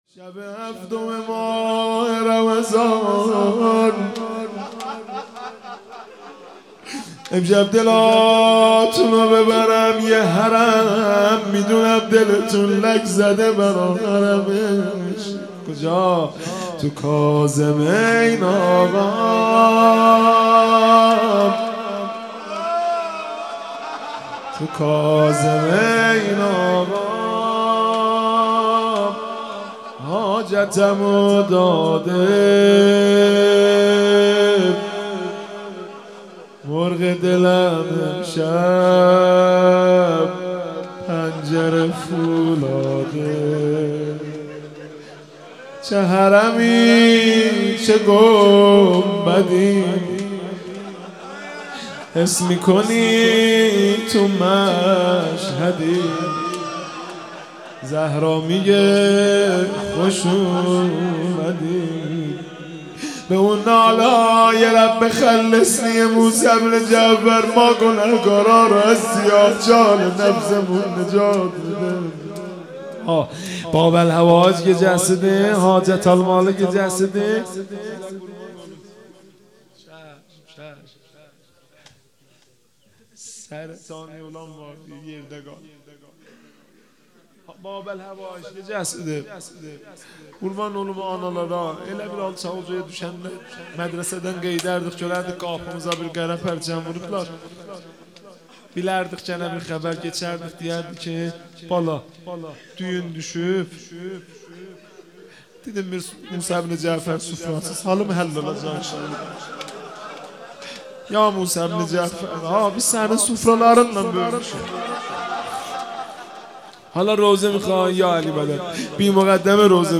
تکیه | مناجات شب هفتم ماه رمضان - مهدی رسولی